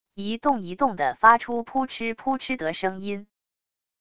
一动一动的发出噗嗤噗嗤得声音wav音频